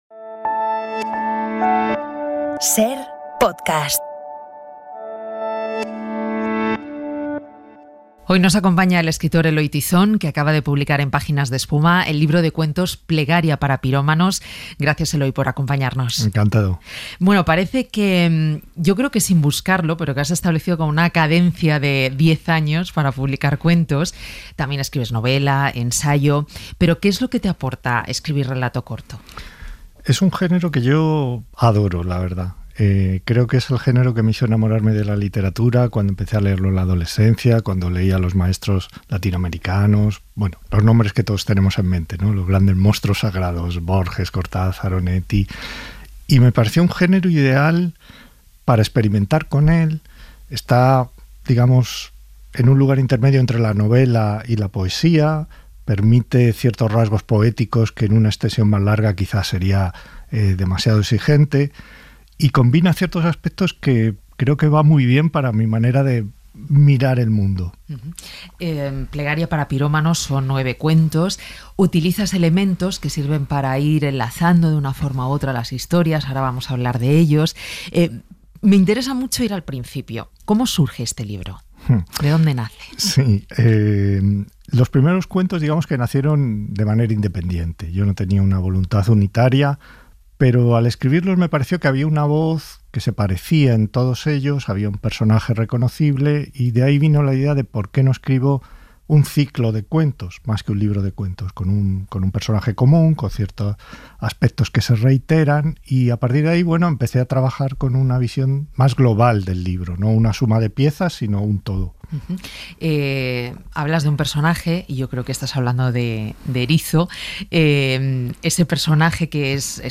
Eloy Tizón, maestro del relato corto, acaba de publicar "Plegaria para pirómanos" en Páginas de Espuma. Un viaje a través de un personaje, Erizo, que utiliza como hilo conductor de las historias para hablarnos de temas como la pérdida, la culpa y la memoria. Una entrevista